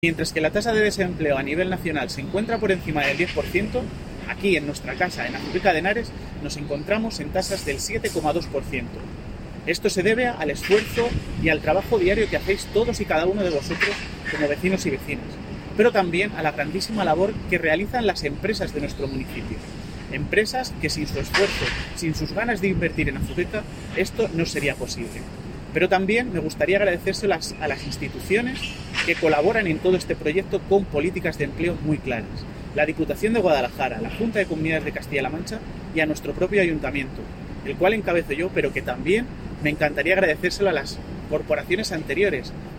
Declaraciones del alcalde, Miguel Aparicio